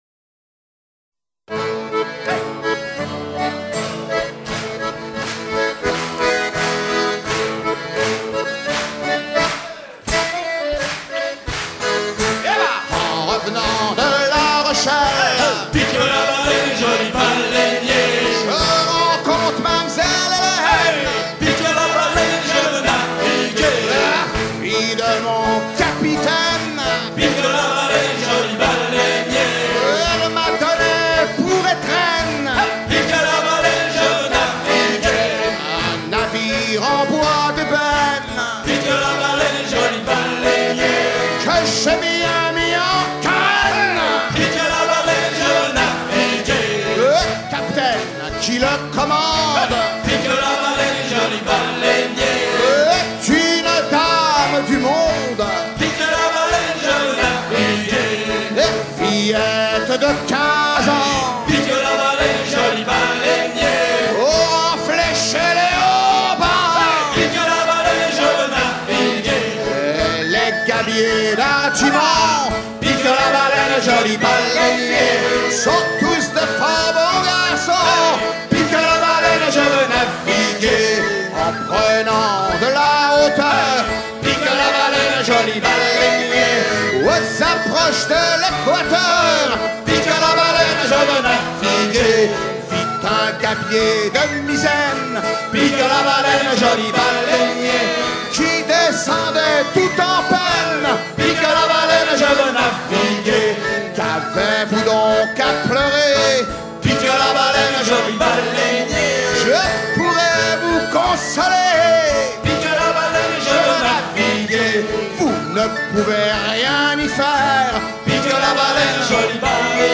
gestuel : à virer au cabestan
circonstance : maritimes
Genre laisse
Pièce musicale éditée